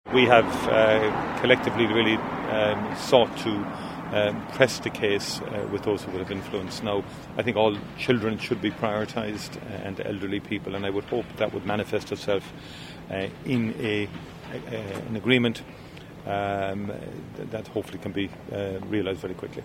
Speaking before today's Cabinet meeting, Tánaiste Micheál Martin says negotiations have been ongoing to secure their release: